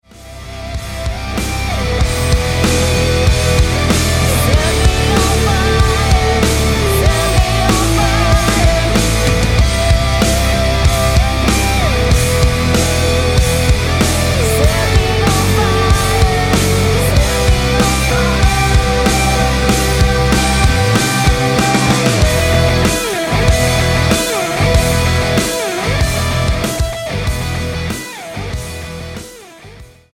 Tonart:Em mit Chor